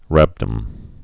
(răbdəm, -dŏm)